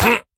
Minecraft Version Minecraft Version snapshot Latest Release | Latest Snapshot snapshot / assets / minecraft / sounds / mob / vindication_illager / hurt3.ogg Compare With Compare With Latest Release | Latest Snapshot
hurt3.ogg